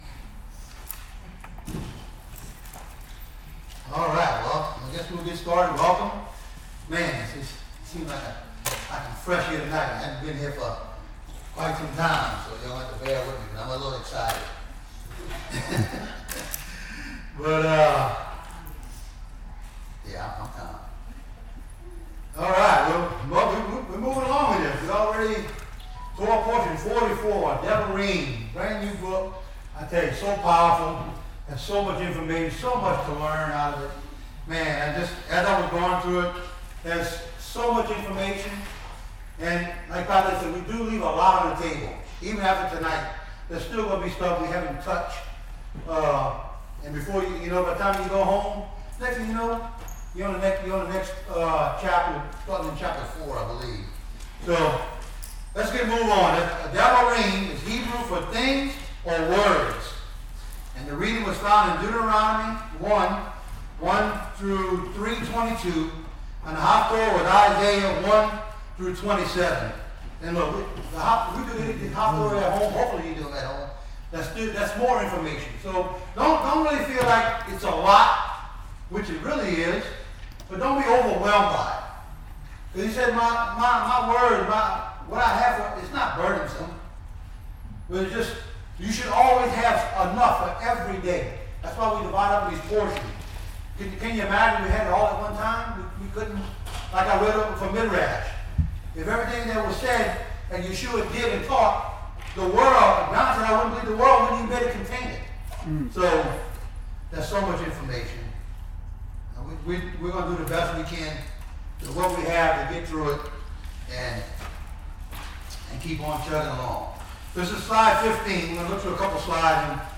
Torah Teachings – Devarim Part 3 – Faith Temple Ministries